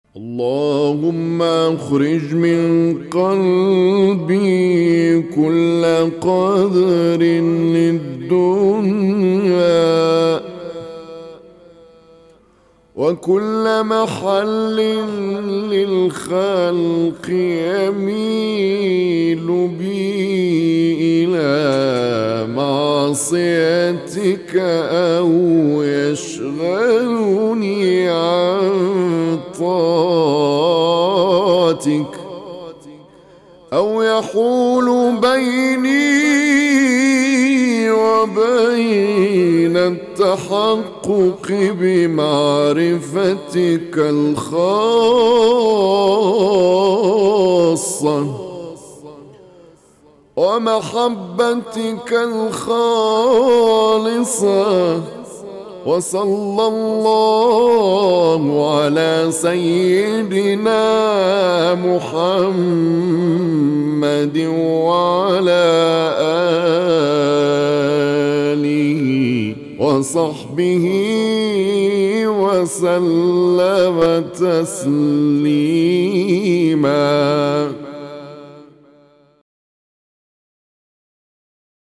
دعاء خاشع يتوجه فيه العبد إلى الله تعالى طالباً التطهير من كل قذر والتفرغ لعبادته. يتضمن الدعاء التوسل إلى الله وسيدنا محمد وآله وصحبه، مع التضرع والانكسار بين يدي الخالق.